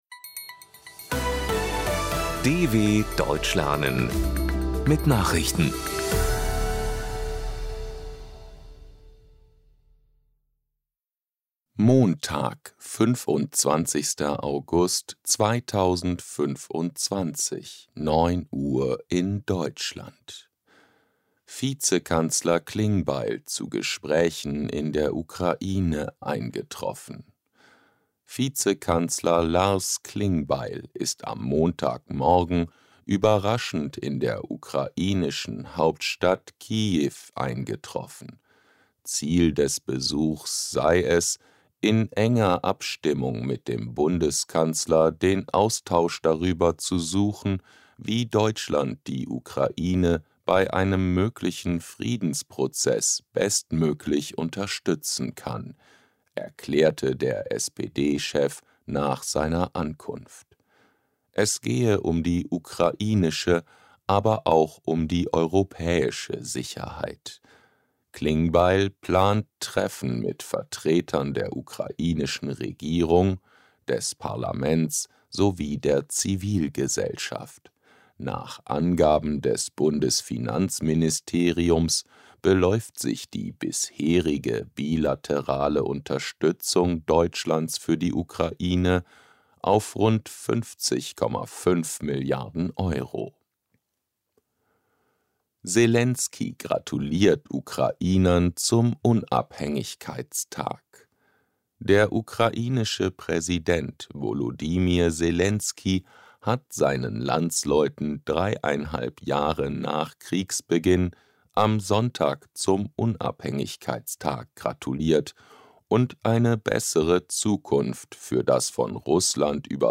25.08.2025 – Langsam Gesprochene Nachrichten
B2 | Deutsch für Fortgeschrittene: Verbessert euer Deutsch mit aktuellen Tagesnachrichten der Deutschen Welle – für Deutschlerner besonders langsam und deutlich gesprochen.